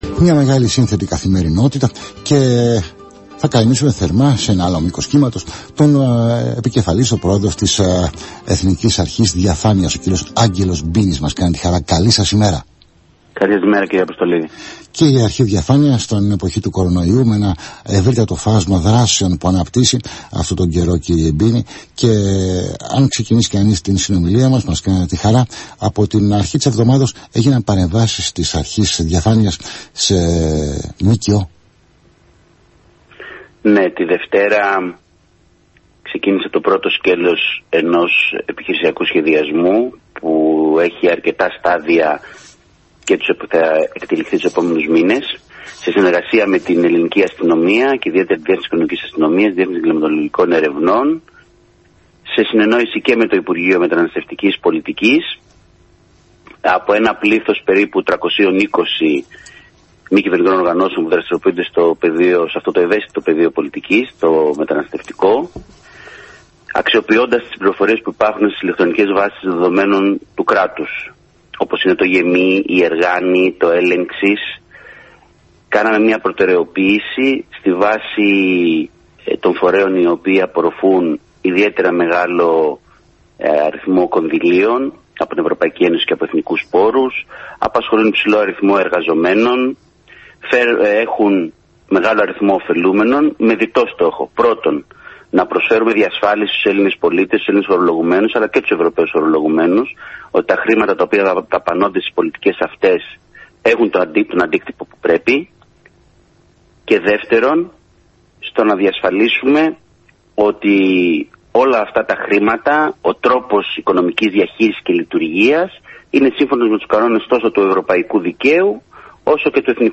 Για τις παρεμβάσεις της Εθνικής Αρχής Διαφάνειας,  σε ΜΚΟ μίλησε  στον Αθήνα 9.84, ο επικεφαλής  της Ανεξάρτητης Αρχής, Αγγελος Μπίνης.